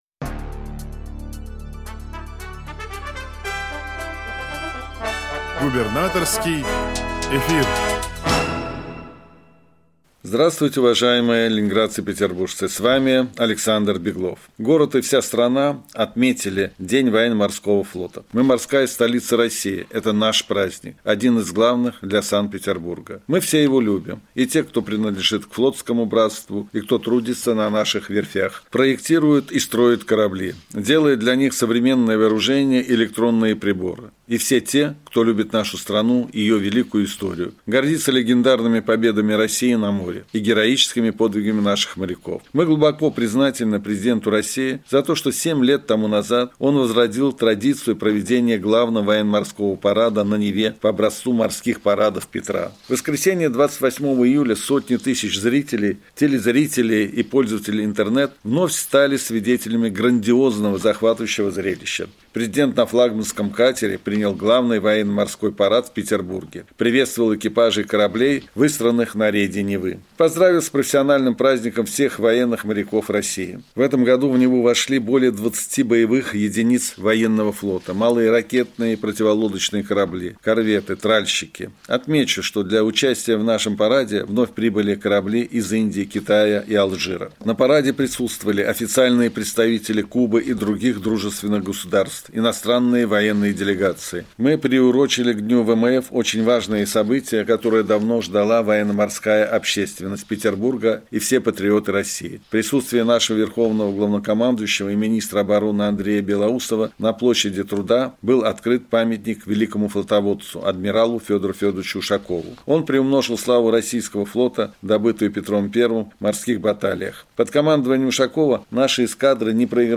Радиообращение – 29 июля 2024 года